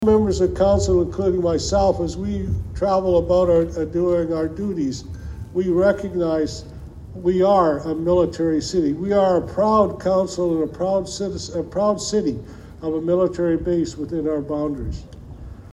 Under bright blue skies Sunday, the mayor of Quinte West Jim Harrison presented the Key to the City to 8 Wing Trenton and the Royal Canadian Air Force.